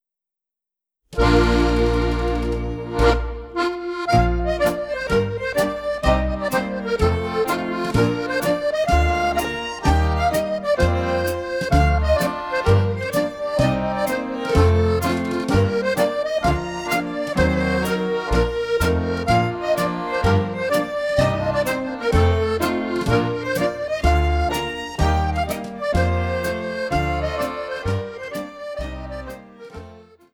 Strathspey